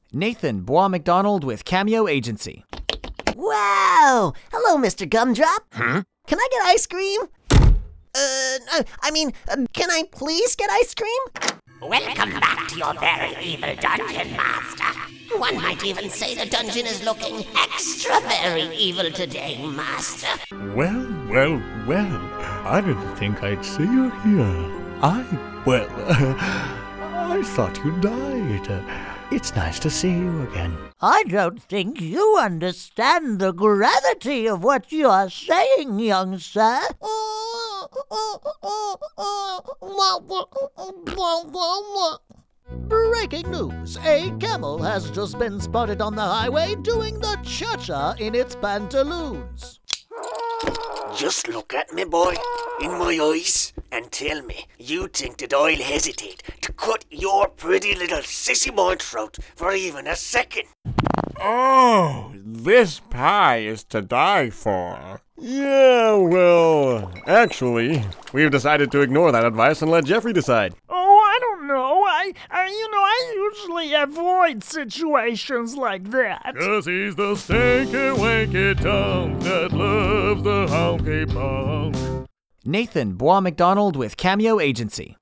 Animation - ANG